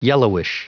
Prononciation du mot yellowish en anglais (fichier audio)
Prononciation du mot : yellowish